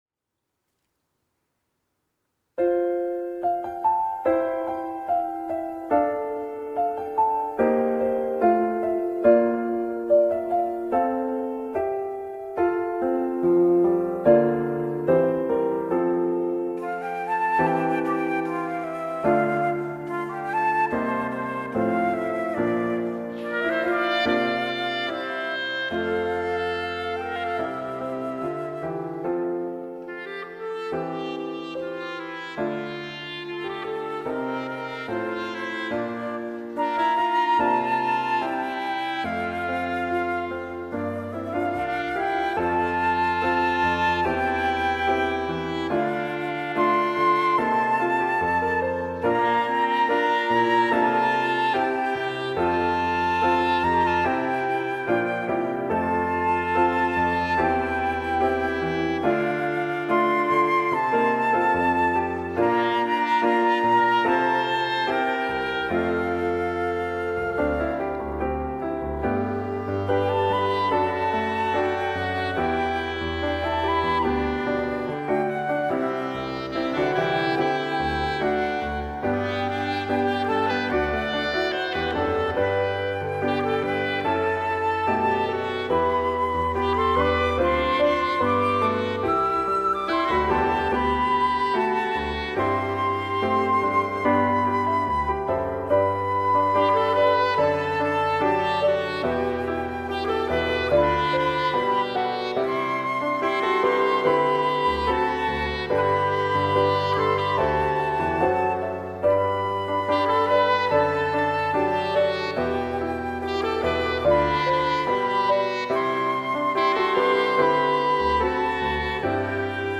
특송과 특주 - 약할 때 강함되시네